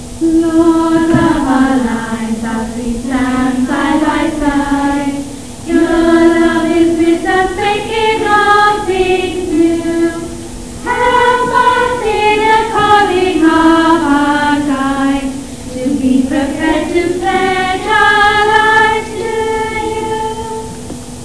Click on any of the following songs to hear us singing: